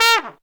Index of /90_sSampleCDs/Zero-G - Phantom Horns/TRUMPET FX 3